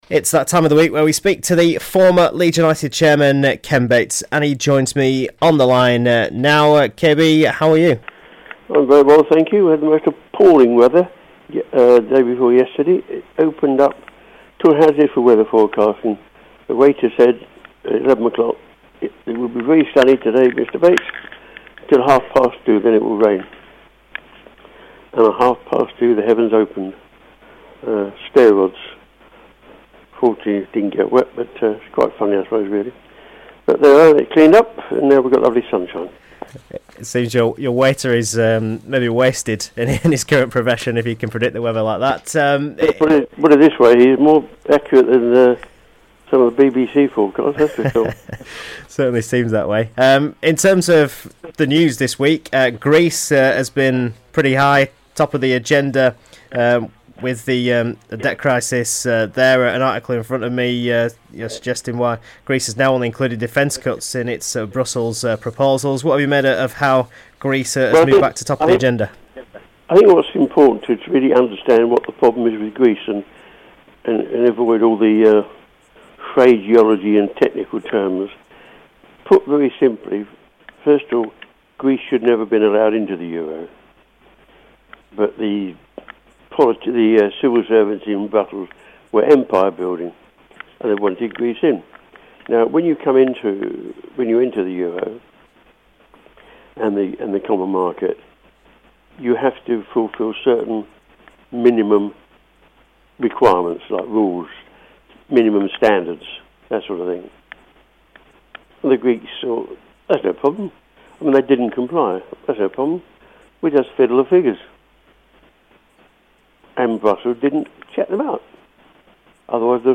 Strong minded Bamba good for Leeds - Ken Bates Interview 25/6 -